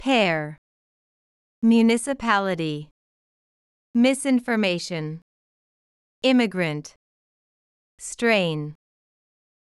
municipality /mjuːˌnɪsəˈpæləti/（名）自治体、市町村
misinformation /ˌmɪsɪnfərˈmeɪʃən/（名）誤った情報
immigrant /ˈɪməɡrənt/（名）（外国からの）移民、入国定住者
strain /streɪn/（名・動）負担、緊張；緊張させる、負担をかける